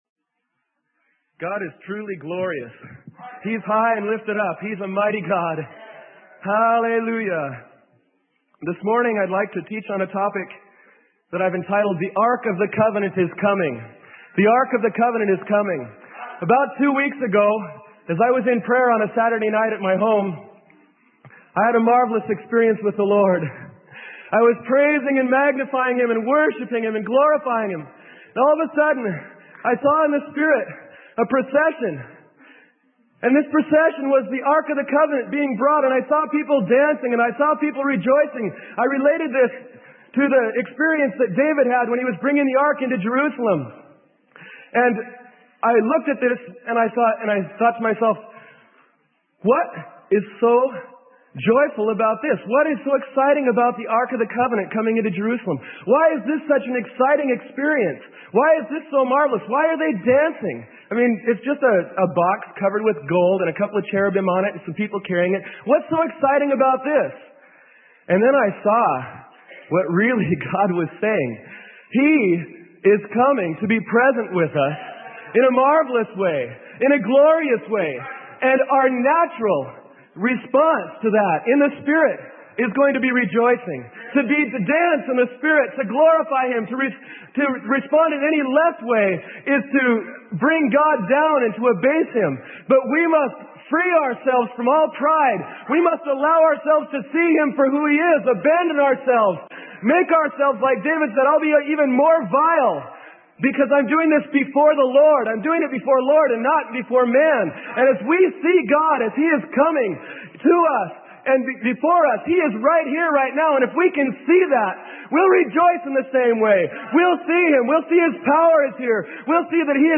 Sermon: The Ark of the Covenant is Coming - Freely Given Online Library